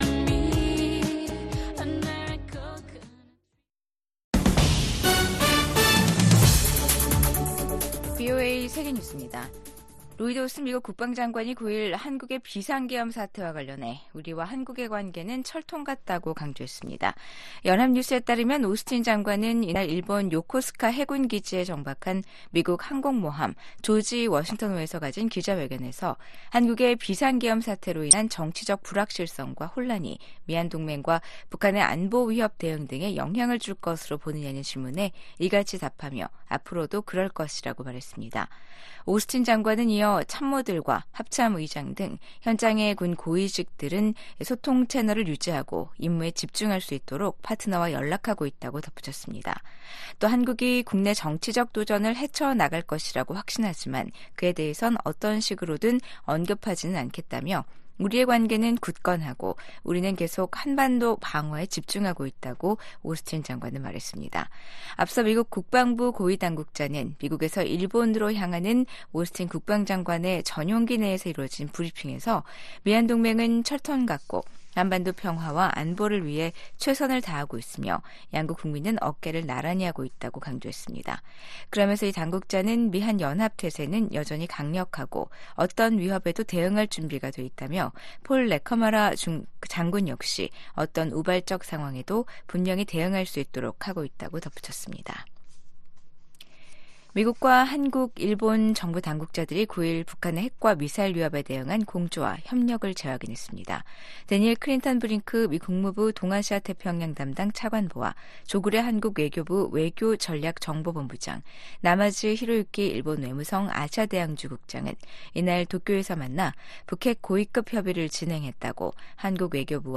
VOA 한국어 아침 뉴스 프로그램 '워싱턴 뉴스 광장'입니다. 윤석열 한국 대통령이 비상계엄 선포 행위로 내란 혐의 피의자가 되면서 국정 공백에 대한 우려가 커지고 있습니다. 미국 정부는 윤석열 대통령의 단호한 대북·대중 정책과 우호적 대일 정책이 탄핵 사유로 언급된 것과 관련해, 한국 민주주의 체제의 원활한 운영이 중요하다는 입장을 재확인했습니다.